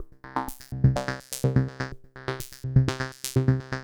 tx_synth_125_pulse_C2.wav